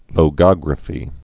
(lō-gŏgrə-fē)